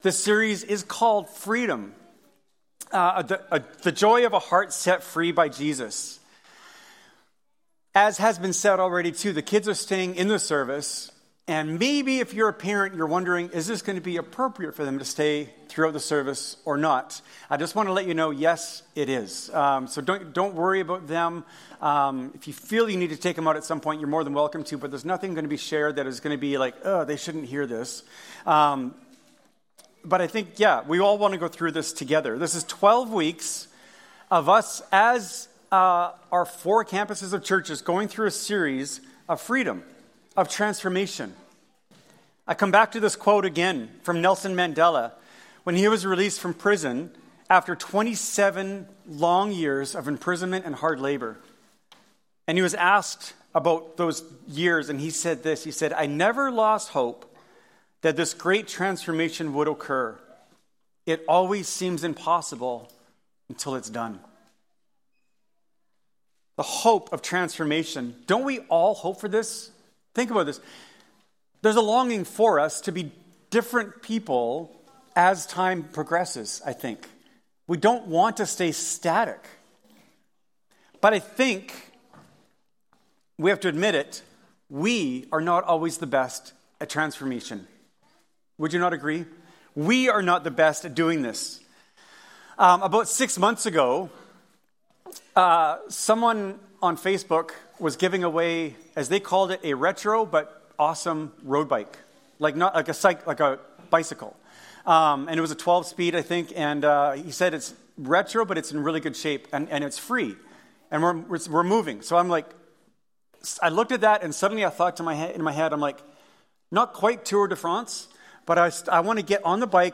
Yorkson Sermons | North Langley Community Church